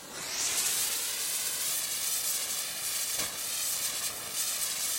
fuse_burning.mp3